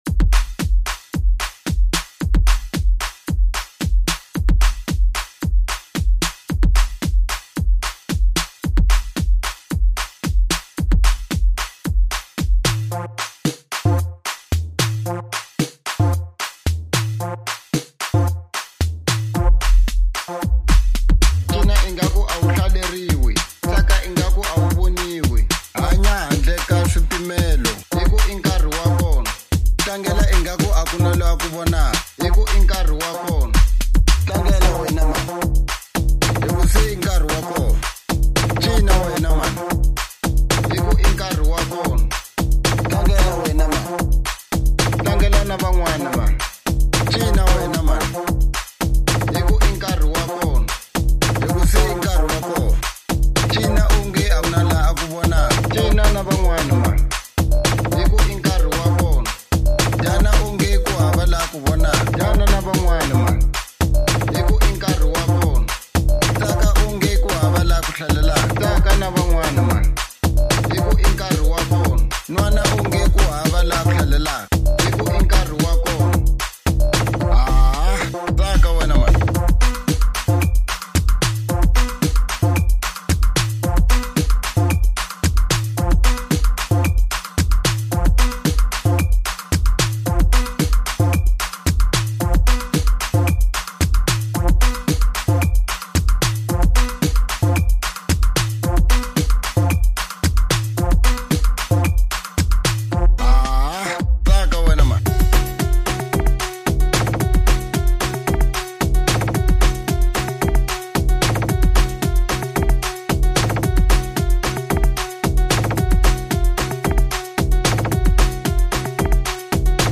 05:35 Genre : House Size